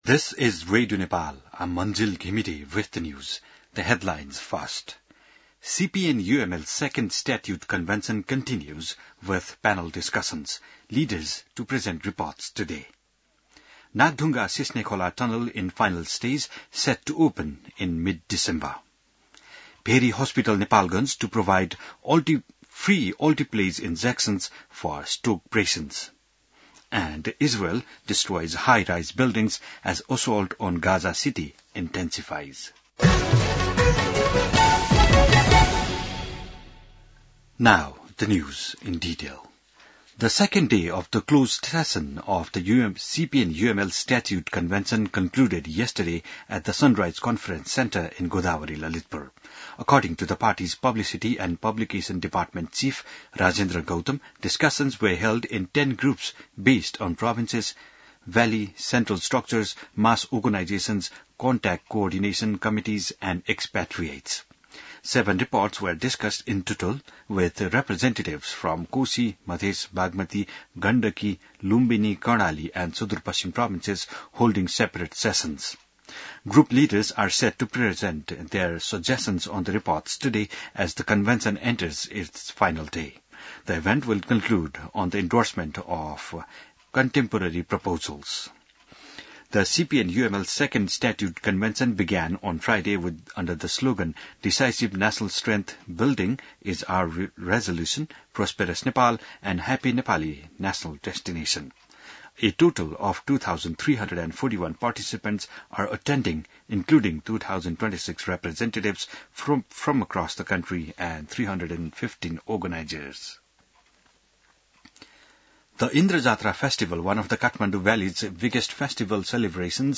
बिहान ८ बजेको अङ्ग्रेजी समाचार : २२ भदौ , २०८२